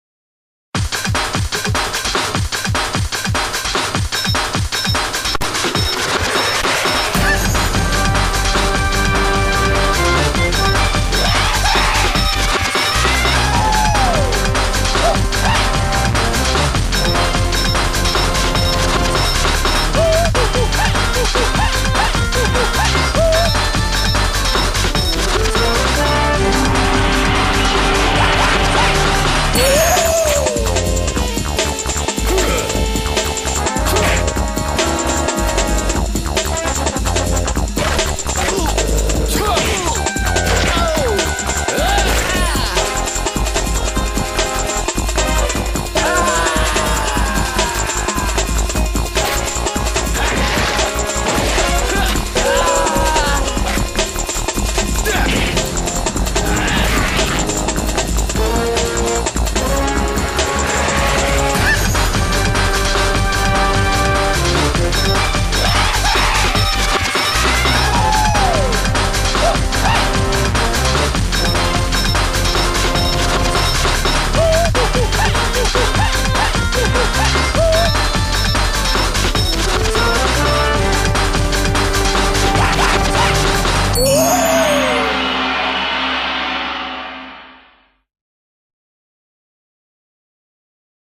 BPM142-160
Audio QualityMusic Cut